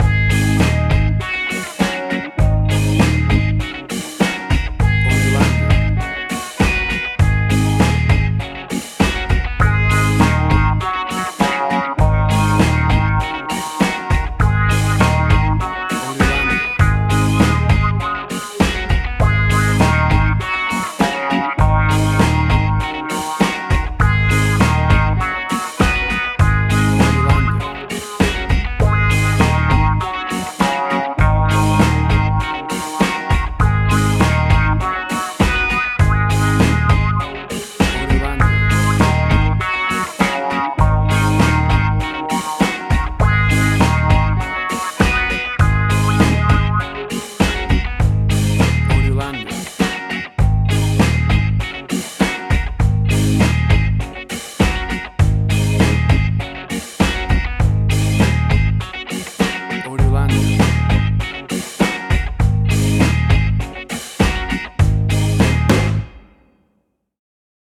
Classic reggae music with that skank bounce reggae feeling.
Tempo (BPM): 100